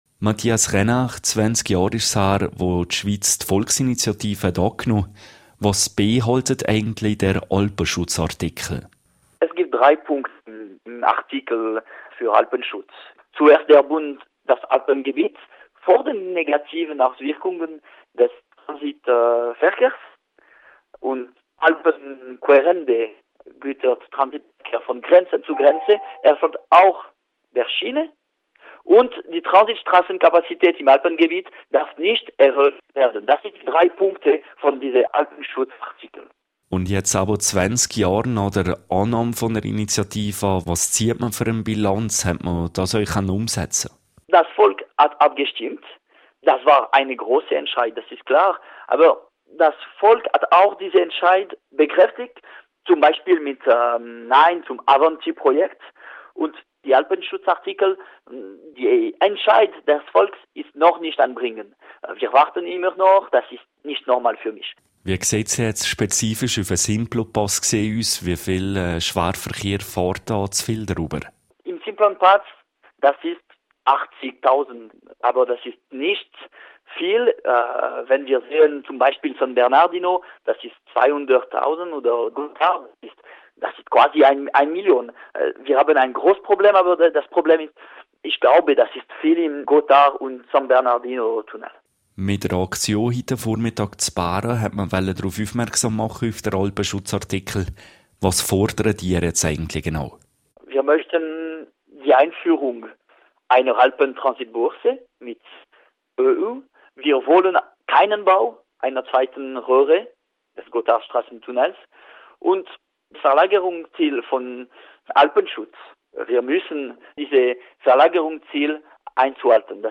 Interview mit Nationalrat Mathias Reynard